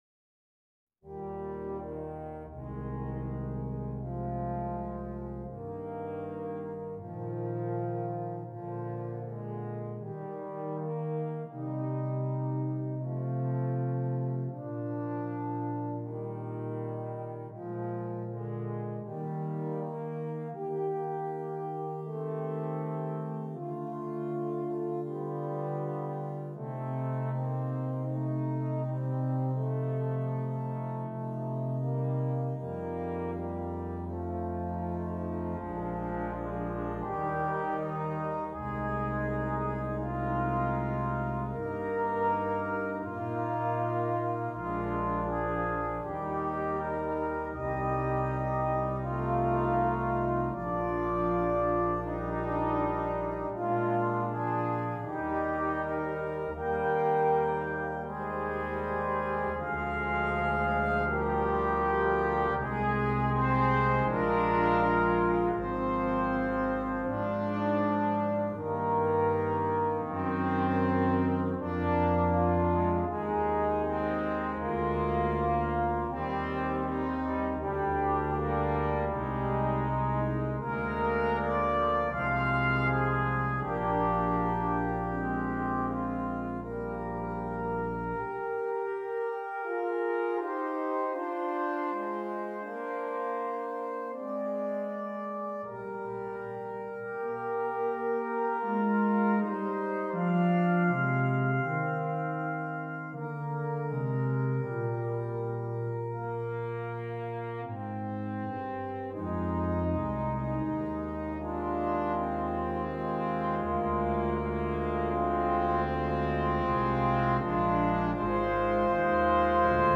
Brass Quintet (optional Timpani)